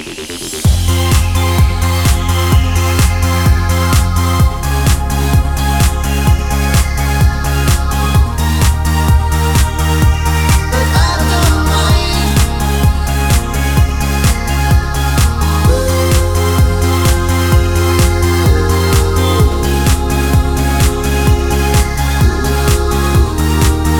no Backing Vocals R'n'B / Hip Hop 4:15 Buy £1.50